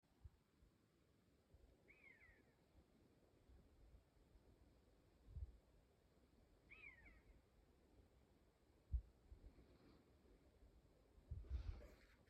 Birds -> Birds of prey ->
Common Buzzard, Buteo buteo
Administratīvā teritorijaValkas novads